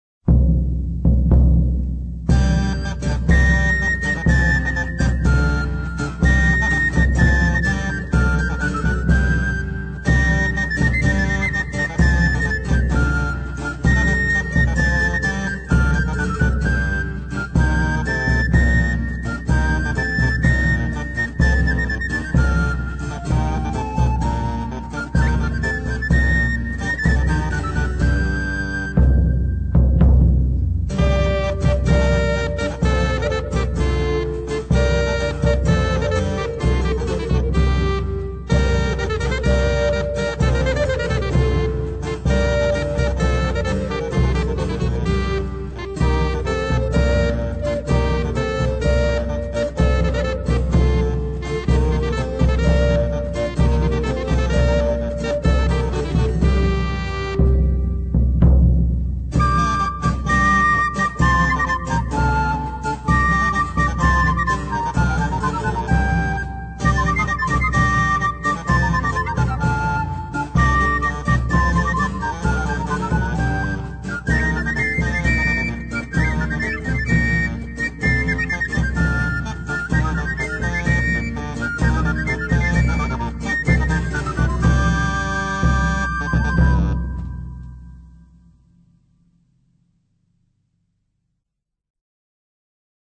Stereo, 1:32, 22 Khz, (file size: 728 Kb).